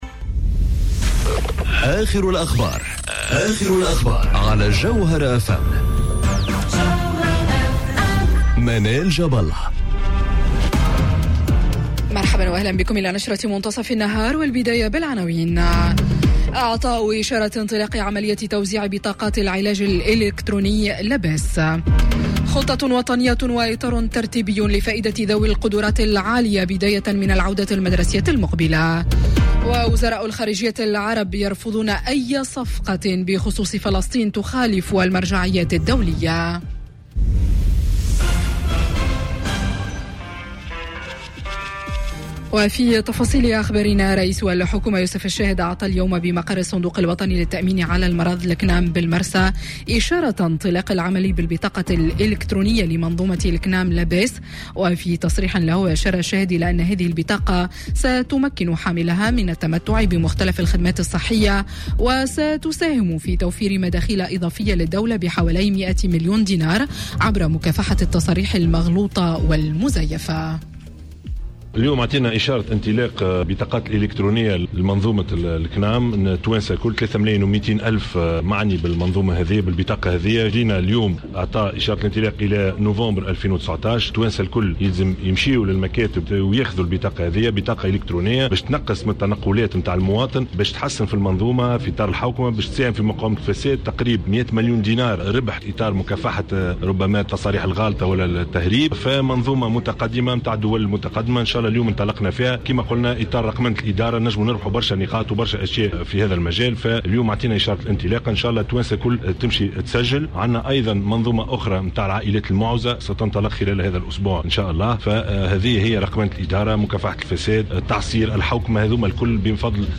نشرة أخبار منتصف النهار ليوم الإثنين 22 أفريل 2019